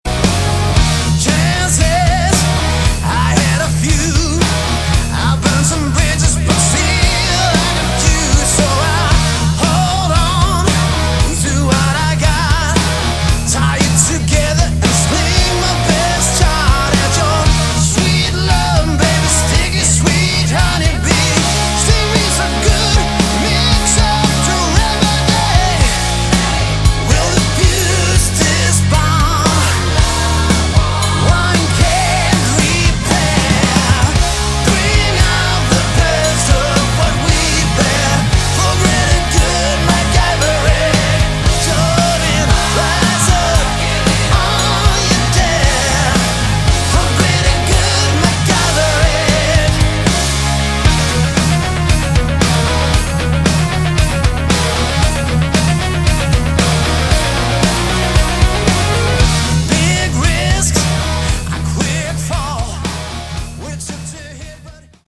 Category: Melodic Rock
guitars, keyboards
lead vocals, guitars
drums
bass
saxophone
backing vocals